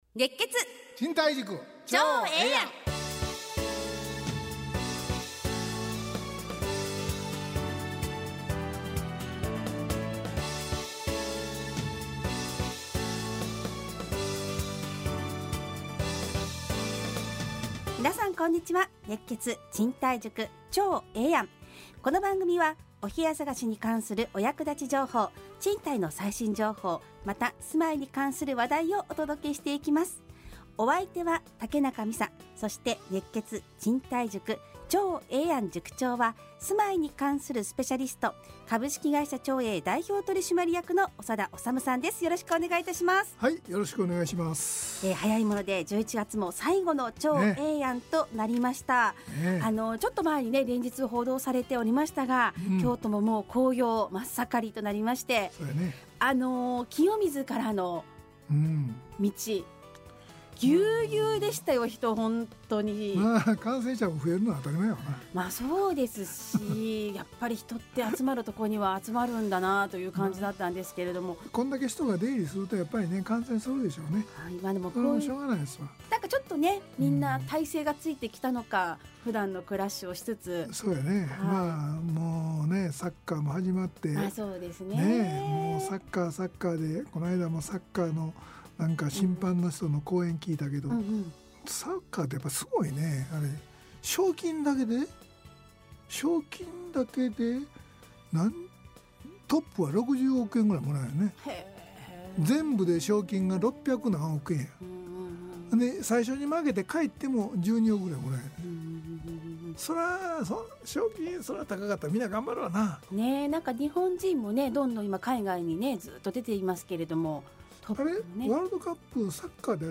ラジオ放送 2022-11-28 熱血！